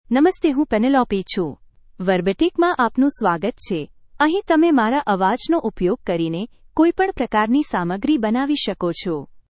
PenelopeFemale Gujarati AI voice
Penelope is a female AI voice for Gujarati (India).
Voice sample
Listen to Penelope's female Gujarati voice.
Penelope delivers clear pronunciation with authentic India Gujarati intonation, making your content sound professionally produced.